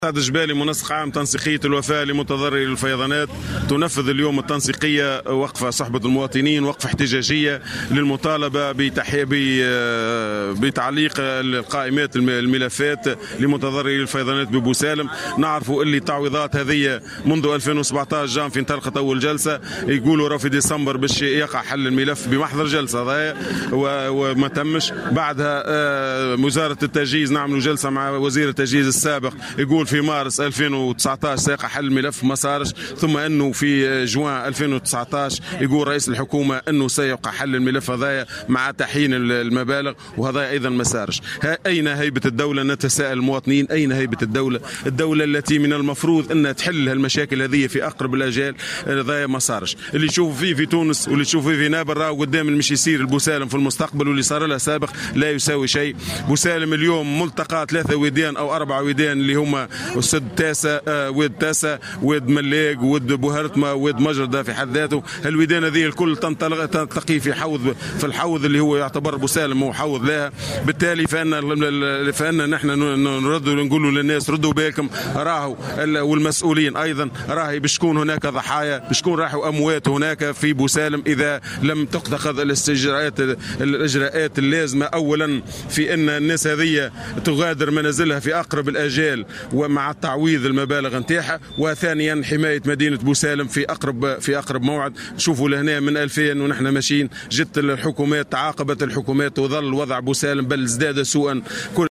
واستنكر المحتجون في تصريحات لمراسل "الجوهرة أف أم" ما اعتبروه "مماطلة" السلط المعنية على الرغم من أن الملف في مراحله الأخيرة ولم يبق إلّا تعليق قائمات المتضرّرين.